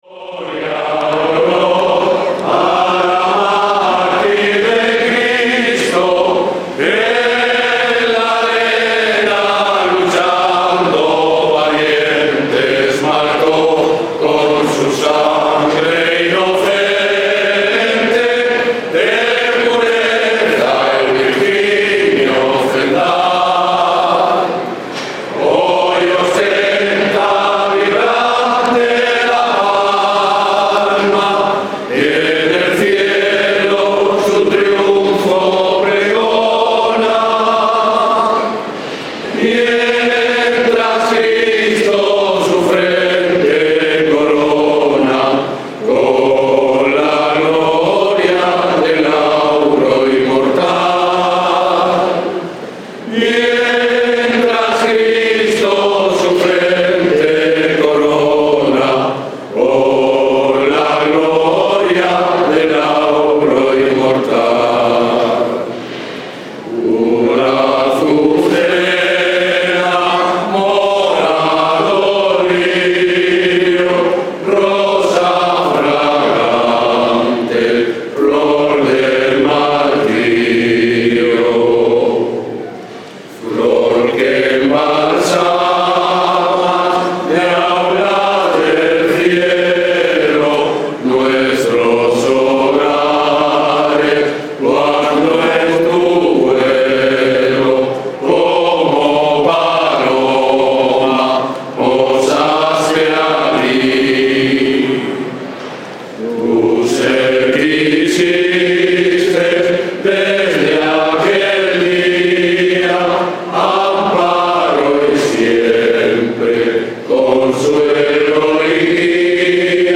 Romería Santa Eulalia. 7 enero 2014. Totana -> El Rulo
Miles de romeros de todas las edades formaban una riada humana por la carretera arropando la imagen de Santa Eulalia en su camino de regreso a la ermita.